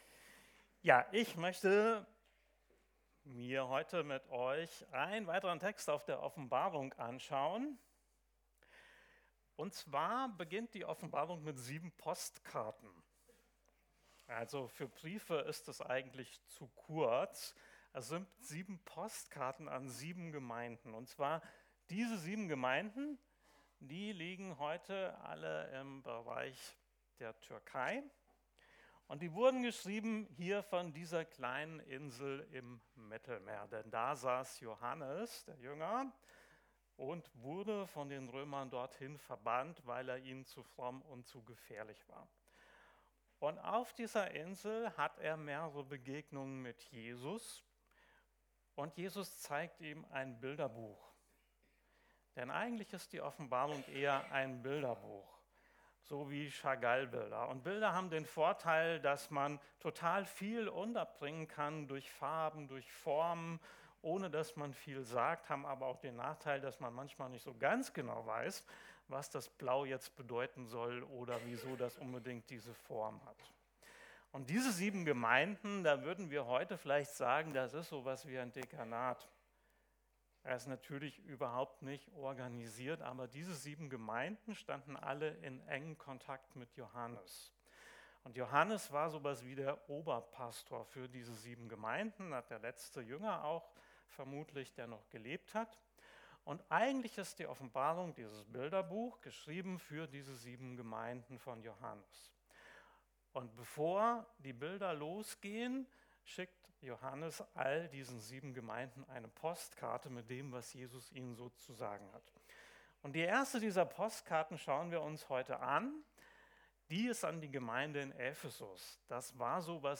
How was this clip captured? Gottesdienst vom 18.01.2026